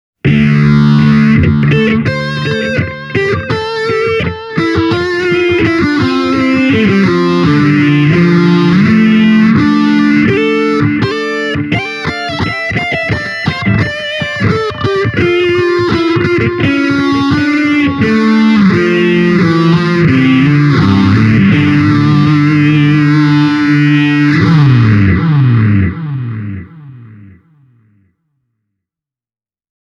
Ääniesimerkit on äänitetty Zoom H1 -tallentimilla.
Myös THR10X:n tapauksessa käytin LP-tyylistä kitaraa:
Yamaha THR10X – Southern Hi + Phaser + Delay